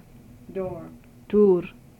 Sound File #1 - The word pronounced in Western Armenian is, "Door". The consonant produced in the word is tʰ. The sound file contains both the English and Armenian pronunciation of the word. Sound file is collected from a larger sound file located in the UCLA Phonetics Archive.